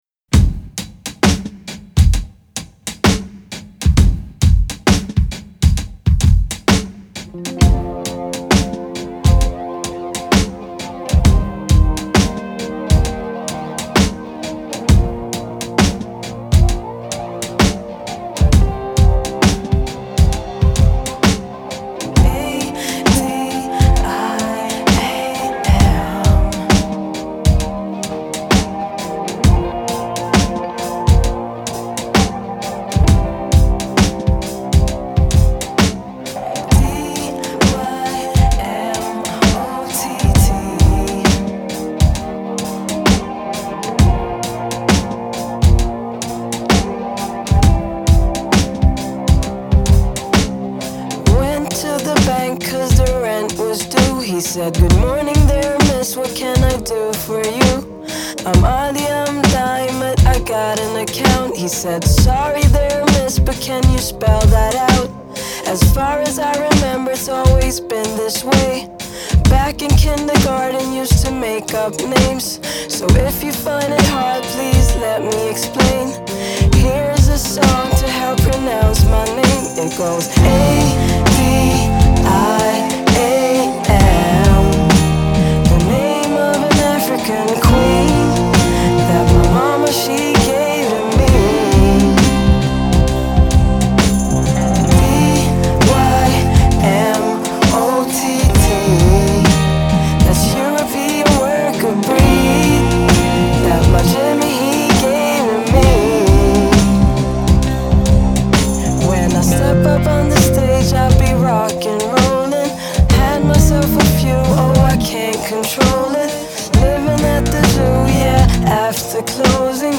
Genre: Indie Pop, Rock, Female Vocal